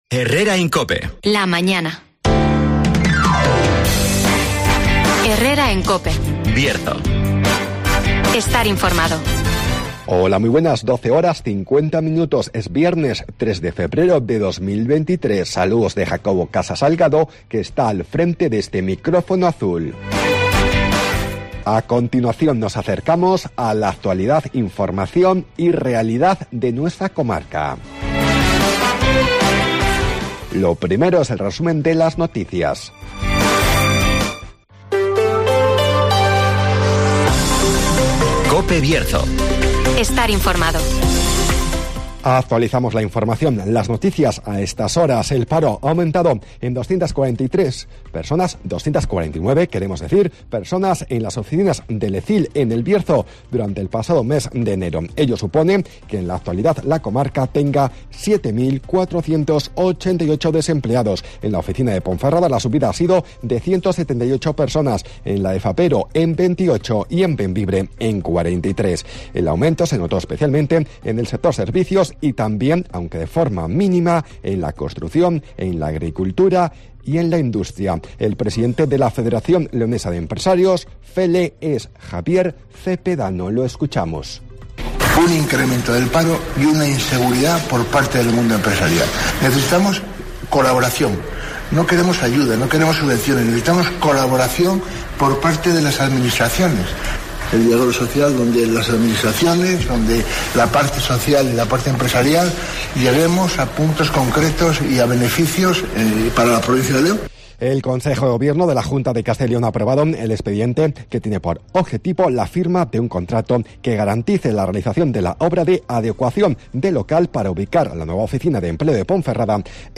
Resumen de las noticias, el tiempo y la agenda.